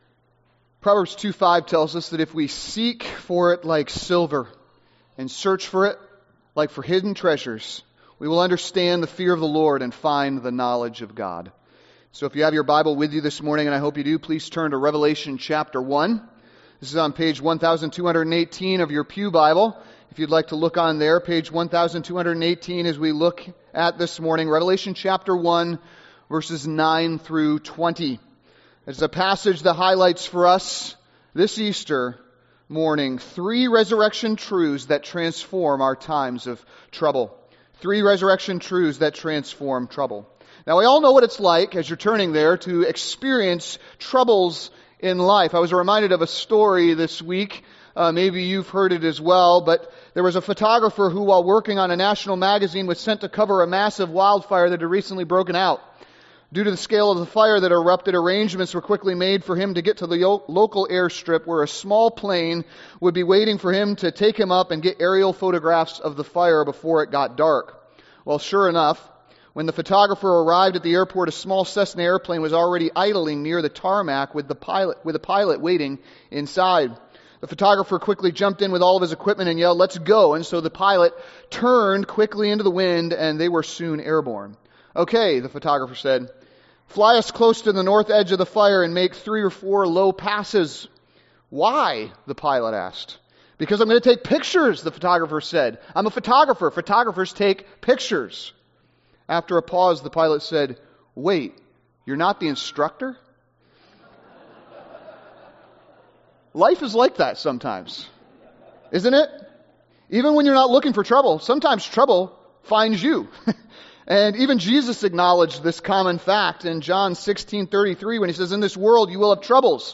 Sermons | Grace Chapel